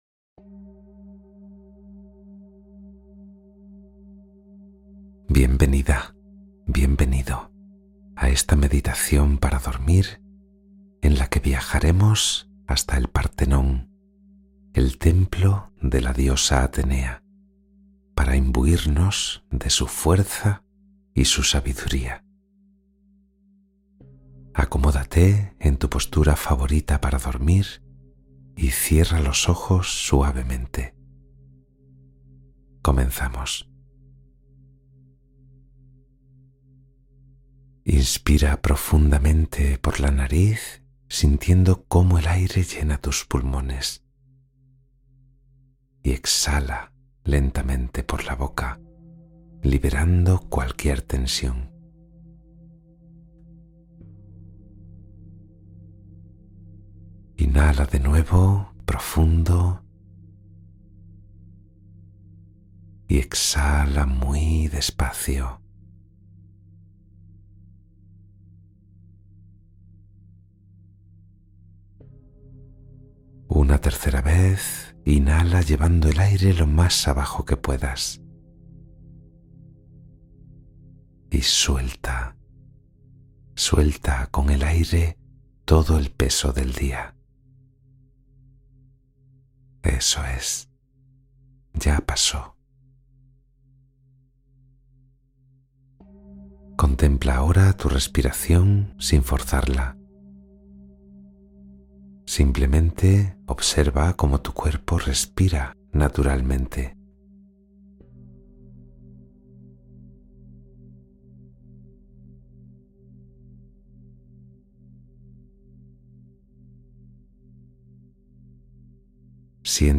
Meditación para dormir con cuento: la diosa Atenea y la fortaleza interior